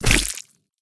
Melee Attack
Melee Swipe #2
096melee2.mp3